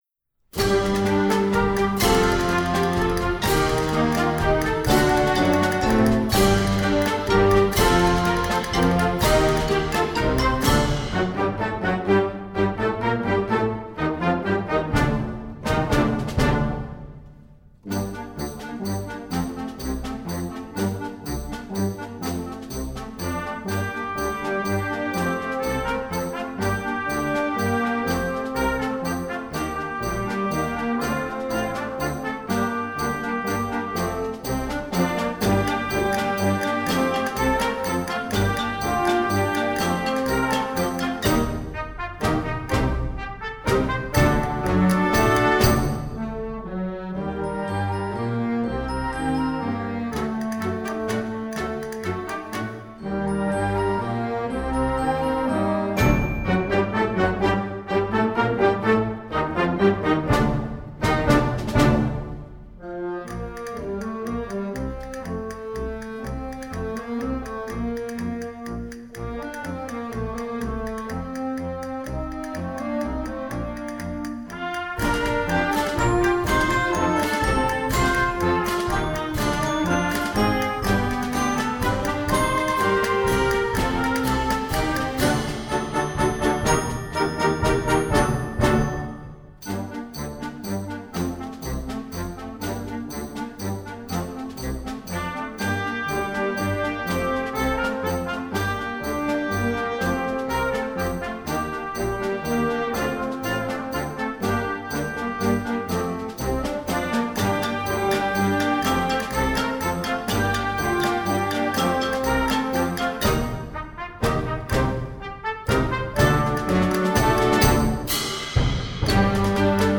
Gattung: für flexibles Jugendblasorchester
Besetzung: Blasorchester